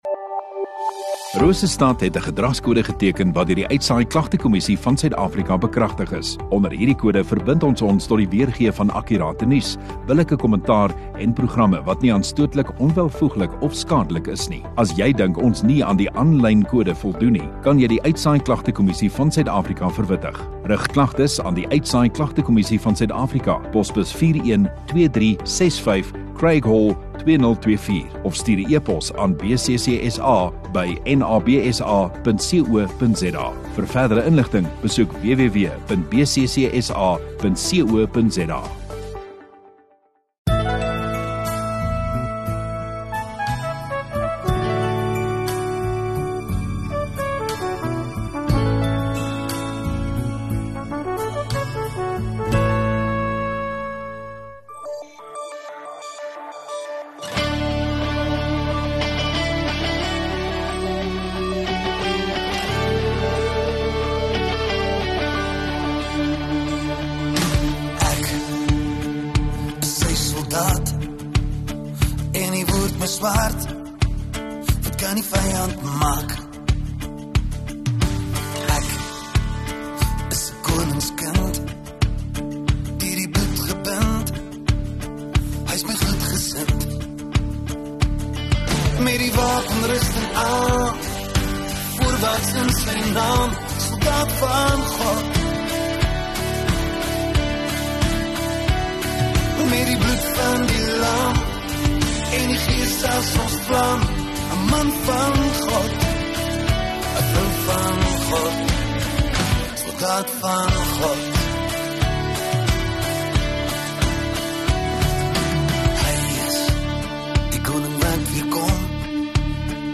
20 Sep Vrydag Oggenddiens